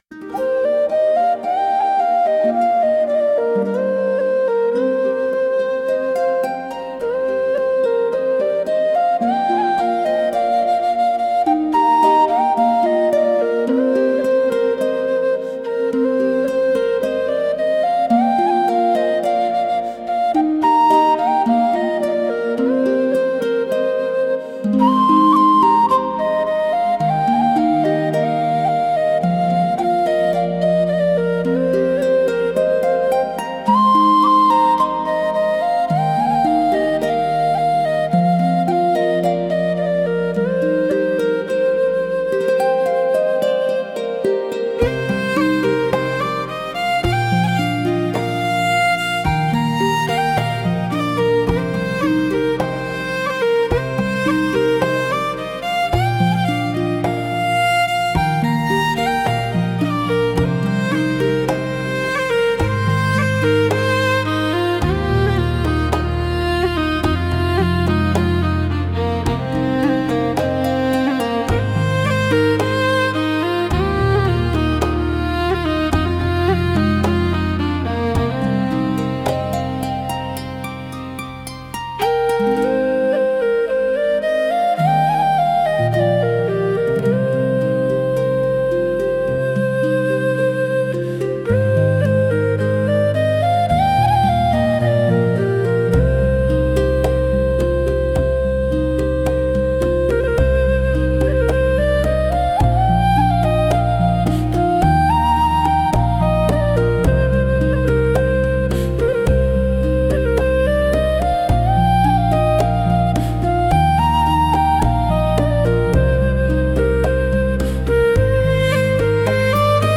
幻想的な森の音楽【癒しBGM】
sunoaiにて作成